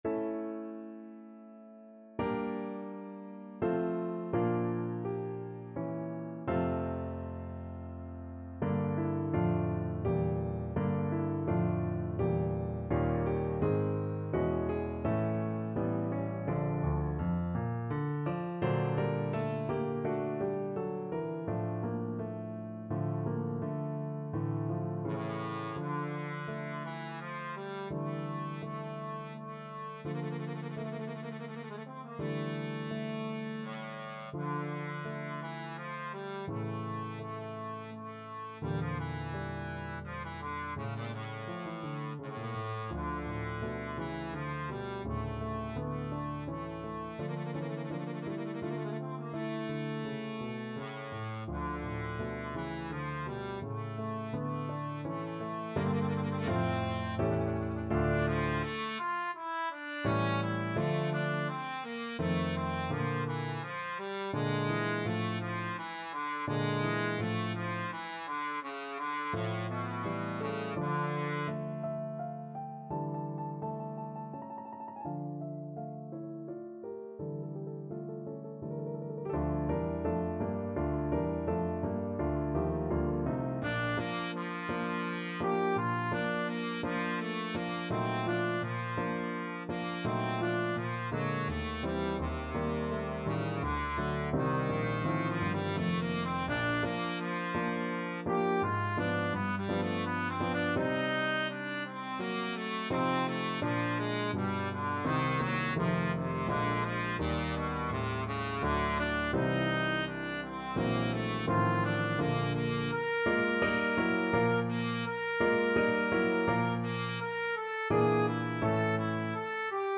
Trombone version
Andante =84
Classical (View more Classical Trombone Music)